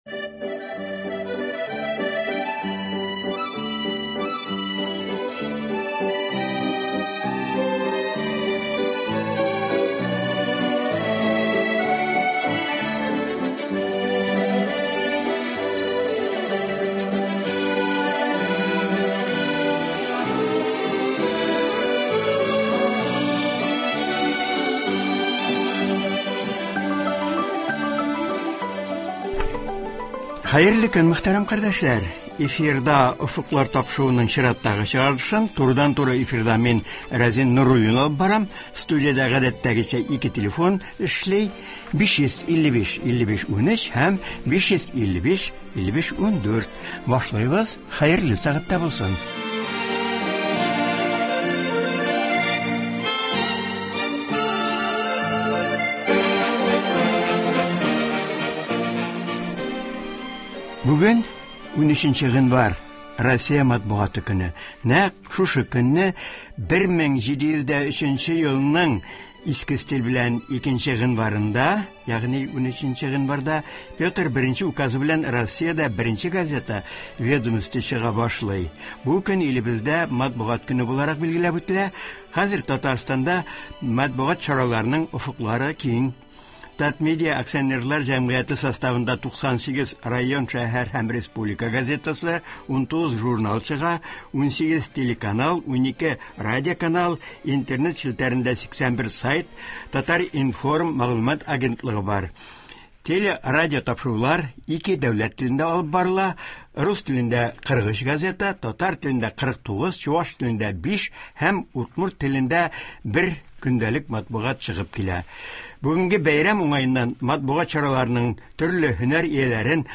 Алар тыңлаучылар сорауларына да җавап бирә.